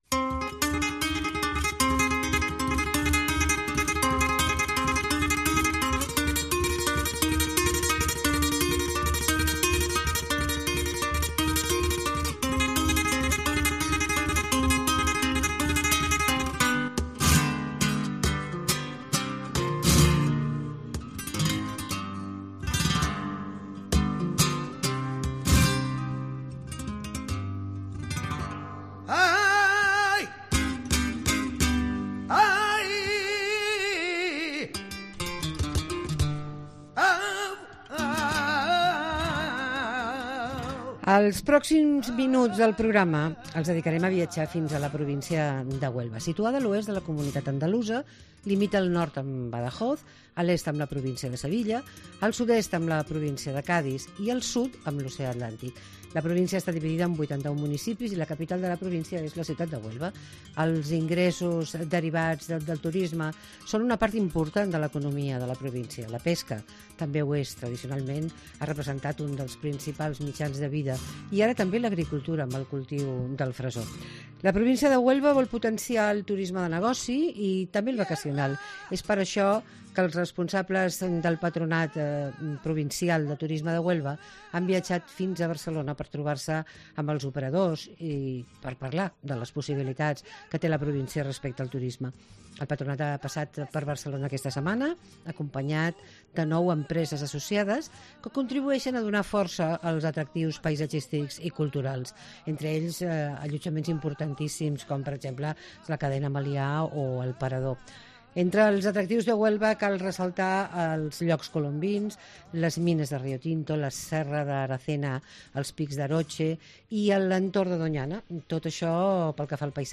Tiramillas Entrevista